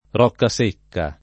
Roccasecca [ r q kka S% kka ]